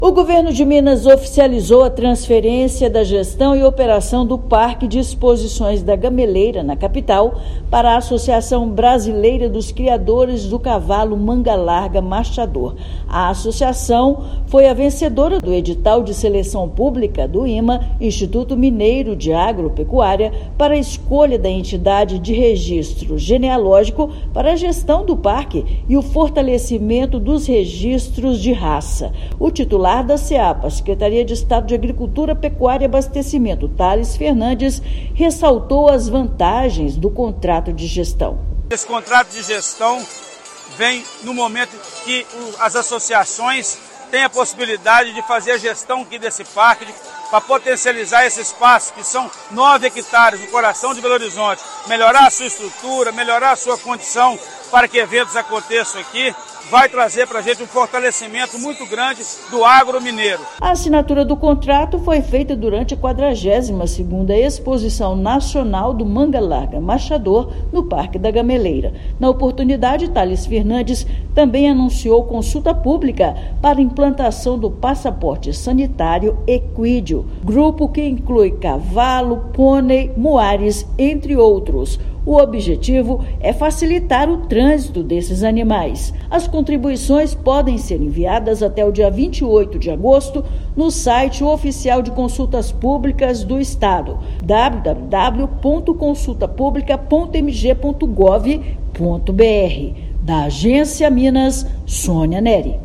Durante evento, neste sábado (26/7), também foram anunciadas medidas para a implantação do passaporte sanitário equídeo entre Minas Gerais, Rio de Janeiro e São Paulo. Ouça matéria de rádio.